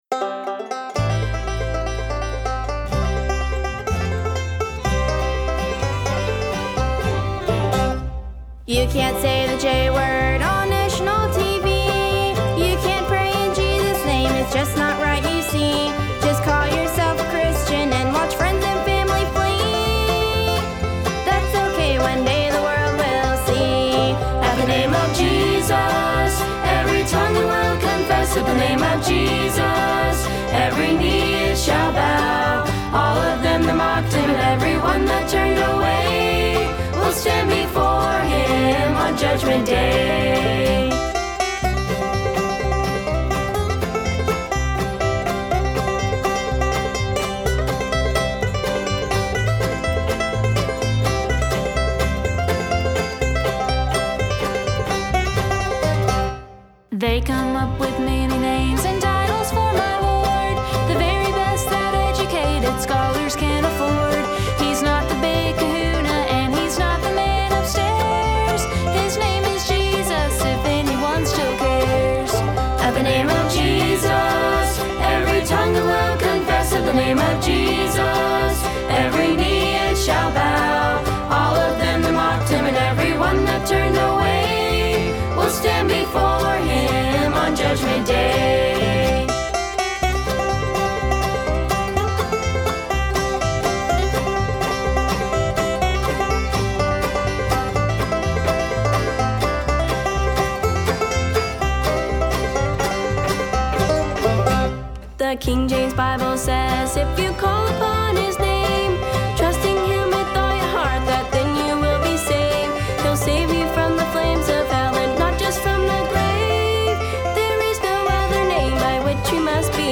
Recorded for Radio